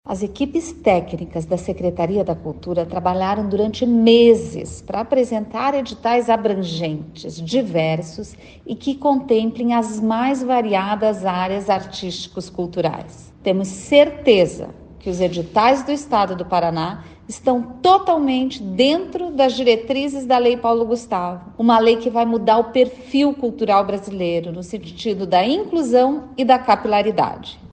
Sonora da secretária da Cultura, Luciana Casagrande Pereira, sobre o conjunto de editais da Lei Paulo Gustavo que passam de R$ 100 milhões no Paraná | Governo do Estado do Paraná